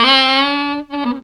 ICEMAN SAX.wav